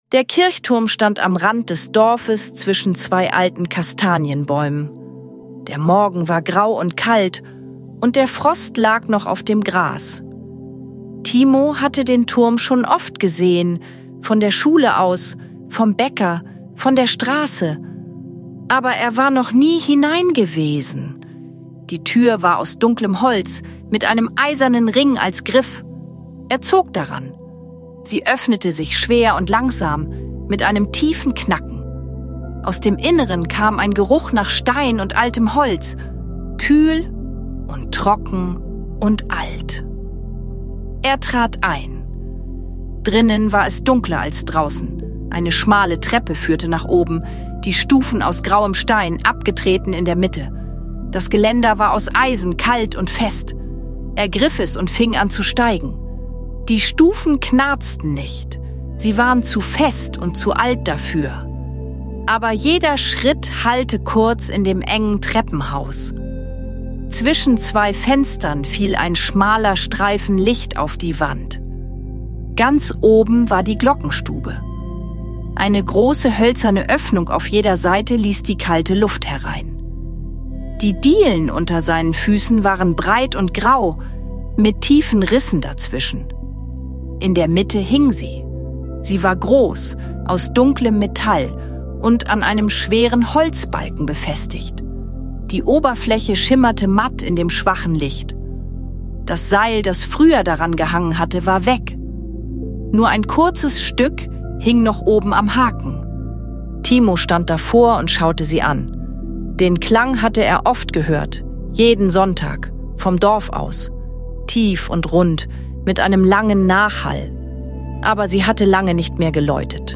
Zwischen Frost, Holz und Wind entfaltet sich eine ruhige Geschichte voller Neugier, Staunen und leiser Geborgenheit. Eine sanfte Gute-Nacht-Geschichte für Kinder, die mit stillen Bildern und ruhiger Musik in den Abend begleitet.